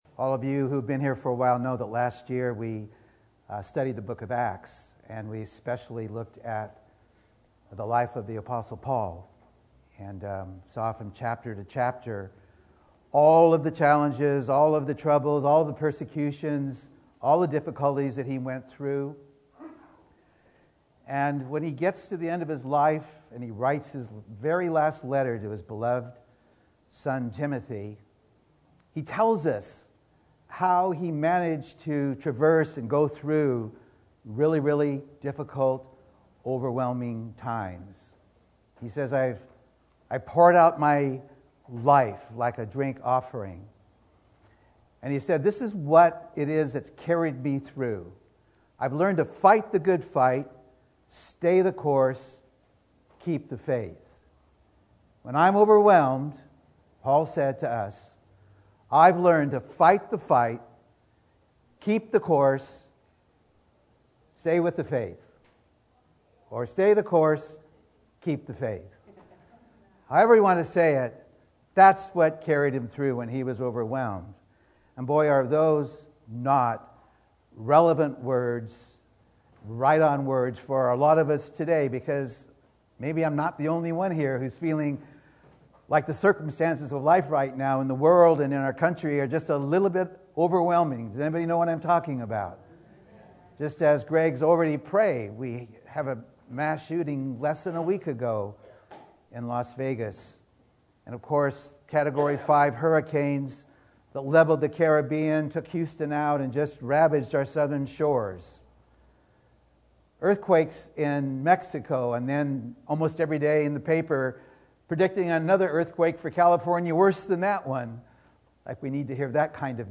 Sermon Notes: When Life Doesn’t Make Sense